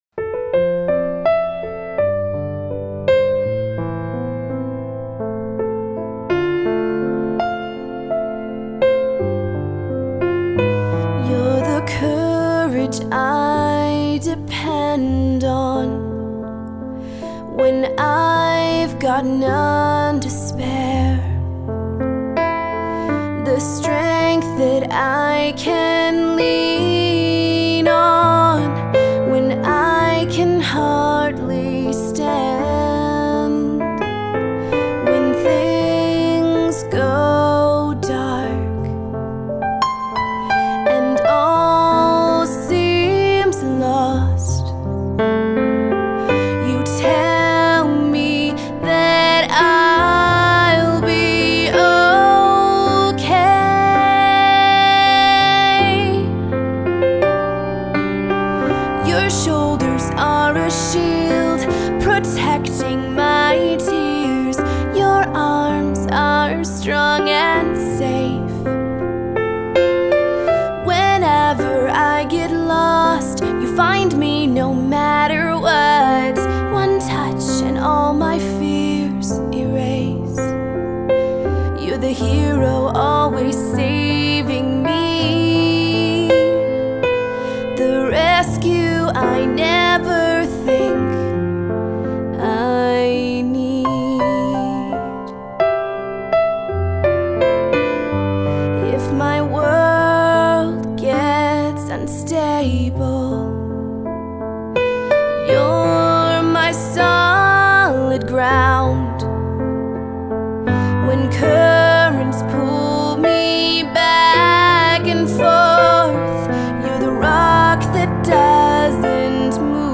country singer-songwriter